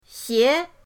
xie2.mp3